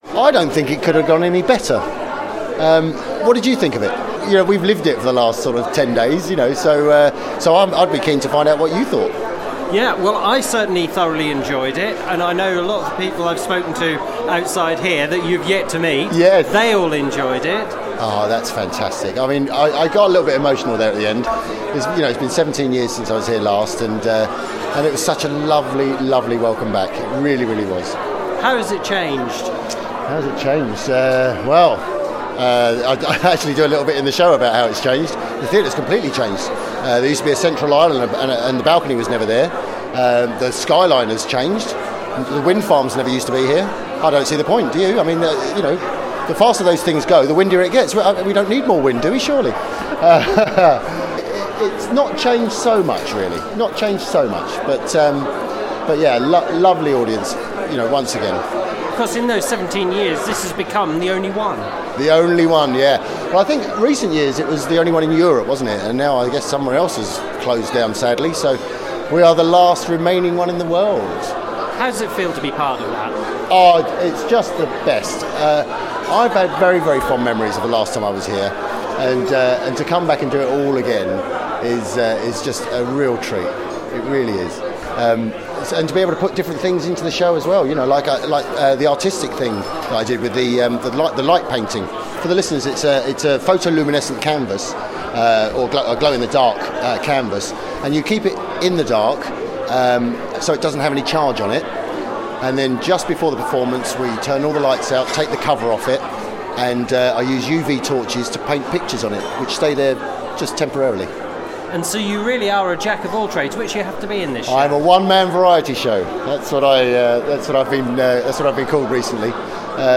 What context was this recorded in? The curtain went up this weekend on Cromer’s end of pier show which is now one of the only shows of its type left.